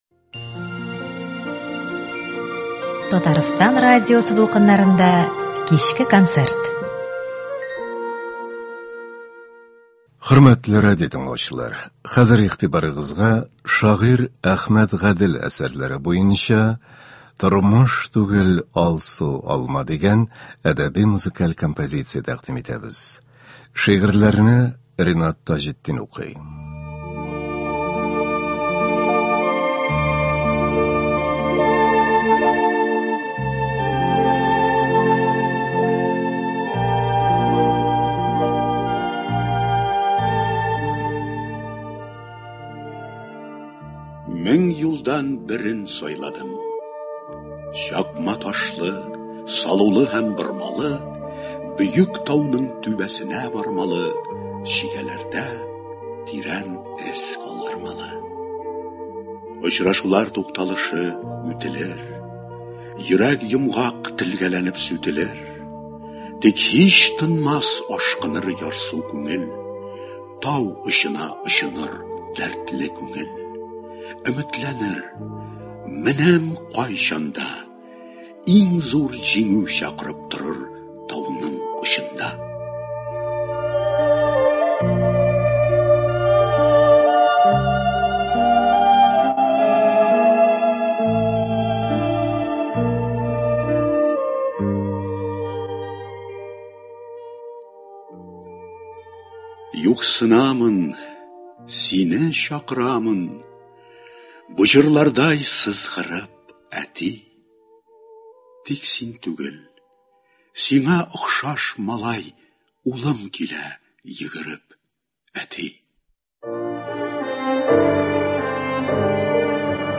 Әхмәт Гадел әсәрләреннән әдәби-музыкаль композиция.